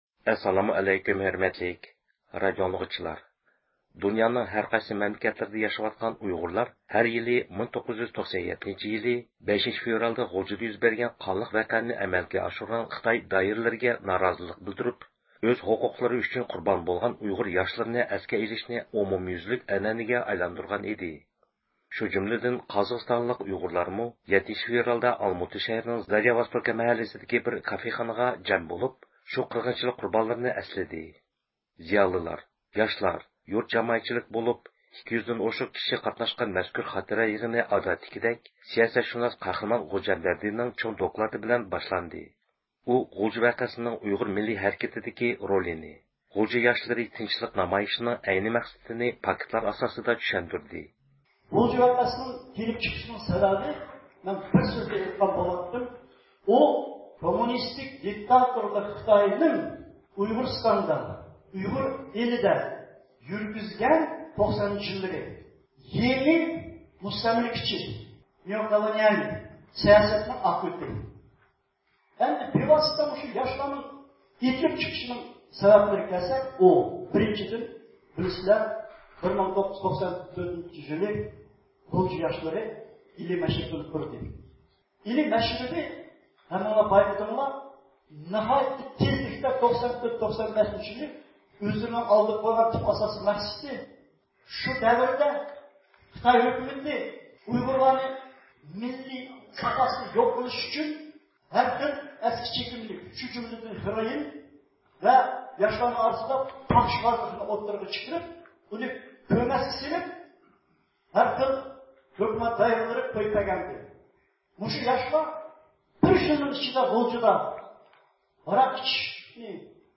شۇ جۈملىدىن قازاقىستاندىكى ئۇيغۇرلارمۇ 7-فېۋرالدا ئالماتا شەھىرىنىڭ زاريا ۋوستوكا مەھەللىسىدىكى بىر كافېخانىغا جەم بولۇپ، شۇ قىرغىنچىلىق قۇربانلىرىنى ئەسلىدى.